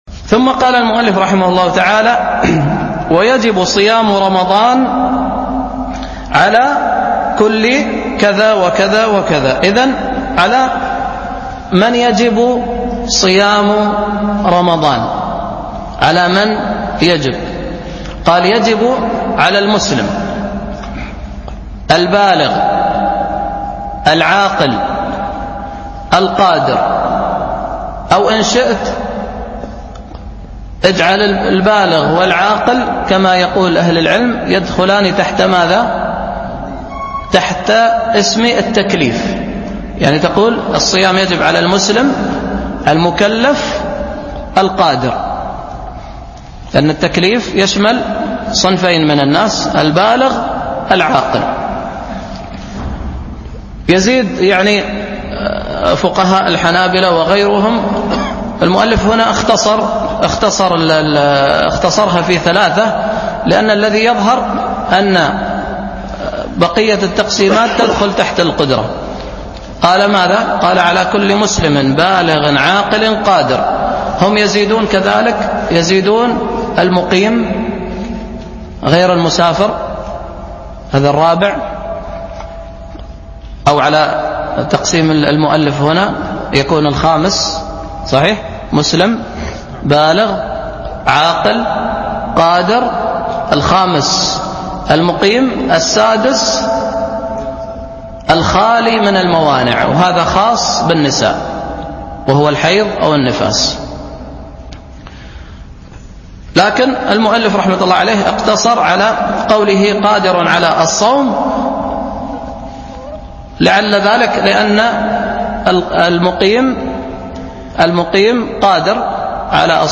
الألبوم: دروس مسجد عائشة (برعاية مركز رياض الصالحين ـ بدبي) المدة: 2:13 دقائق (545.4 ك.بايت) التنسيق: MP3 Mono 22kHz 32Kbps (VBR) ▸ الصيام في الإصطلاح فوق وجوب صيام رمضان على كل مسلم ◂ حمل الملف الصوتي